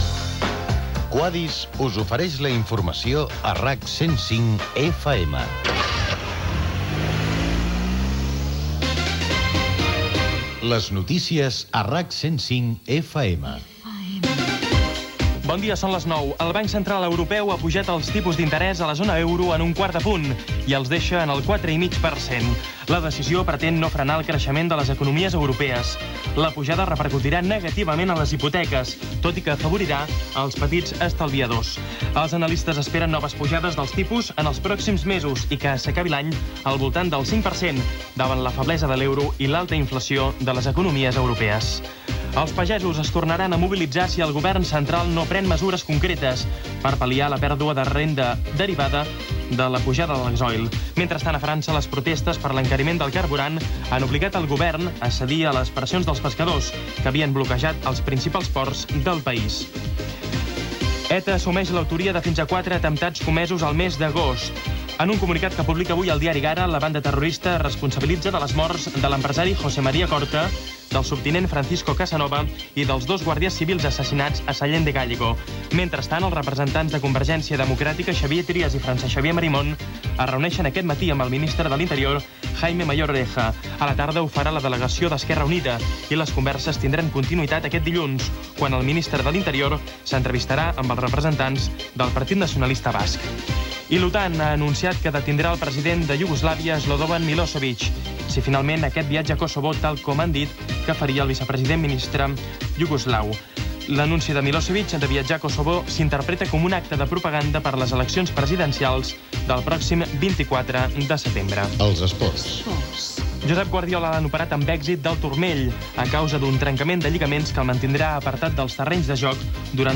Careta del programa amb publicitat, informacions de: Banc Central Europeu, les protestes dels pagesos, ETA, OTAN, els esports, el temps i el trànsit
Informatiu
FM